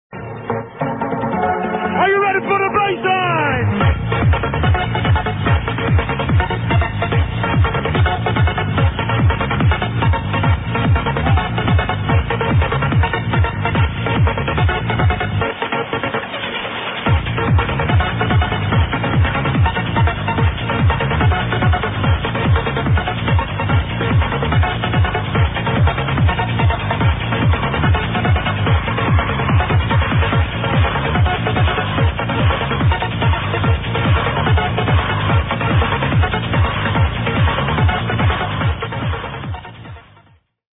This one sounds much like a typical dutch techtrancer... anyone knows it?